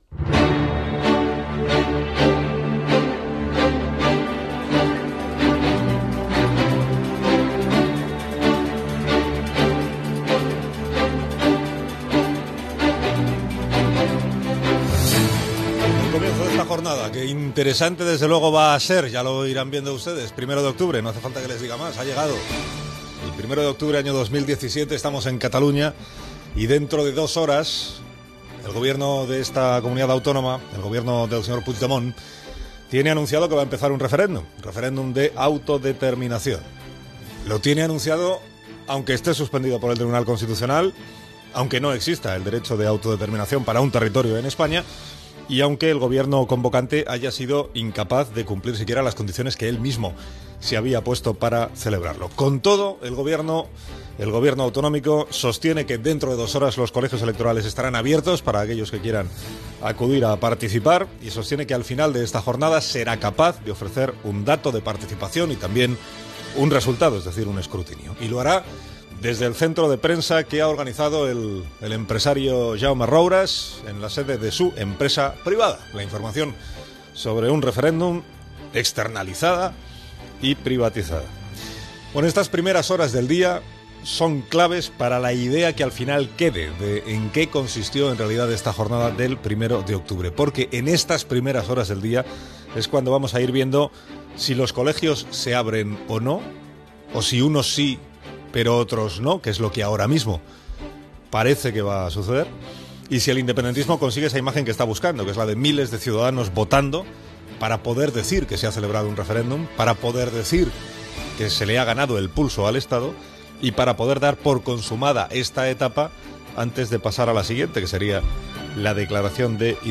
Especial informatiu el matí del dia del referèndum de l'1 d'octubre a Catalunya. Opinió de Carlos Alsina sobre la jornada, situació als col·legis electorals, Connexió amb el col·legi electoral de La Sedeta.